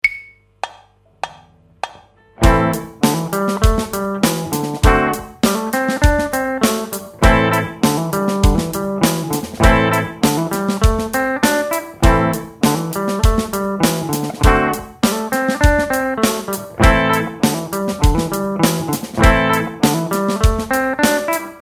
The example here uses the minor pentatonic scale to play blues based licks with the A minor and D7 bar chords.